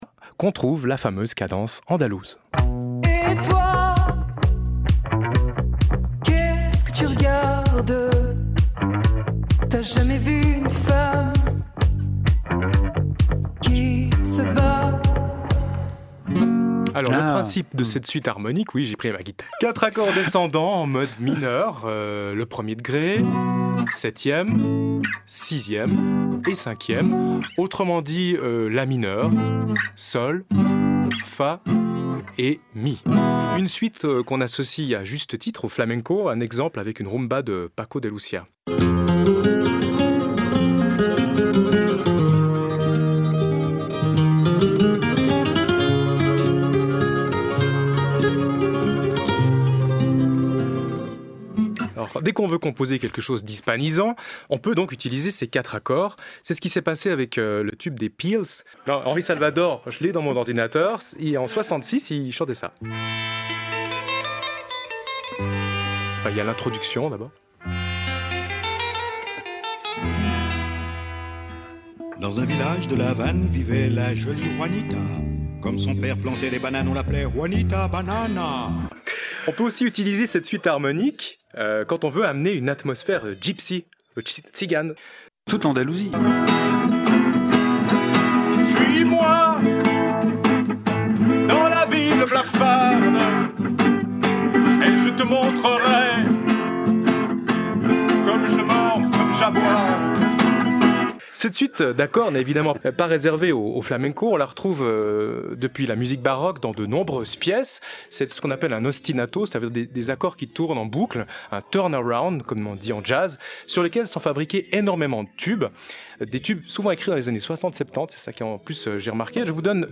La cadence andalouse
Comparaison Guitare et Oud (luth arabe)
cadence-andalouse.mp3